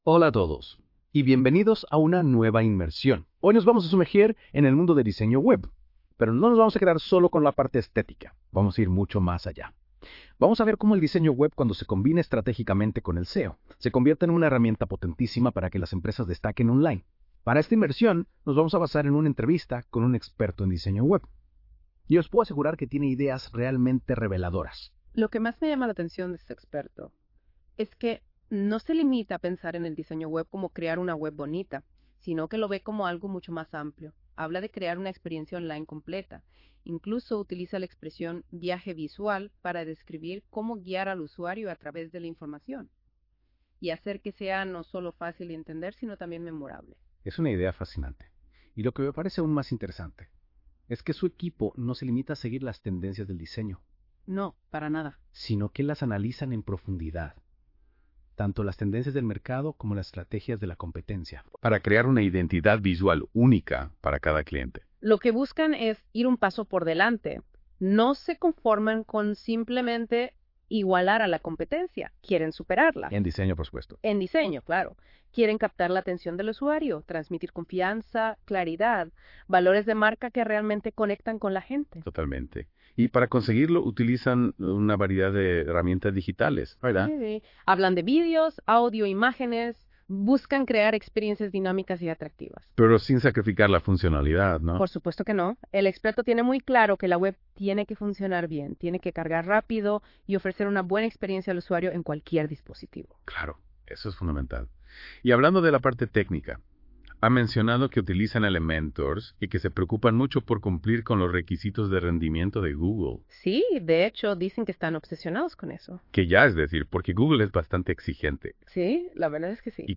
Exploramos cómo el diseño web y el SEO se combinan para optimizar la presencia digital, en un formato de audio creado con inteligencia artificial.
Utilizando inteligencia artificial, hemos transformado la conversación de este contenido escrito en un audio que puedes escuchar en cualquier momento.
Diseno-Web-Conversacional.mp3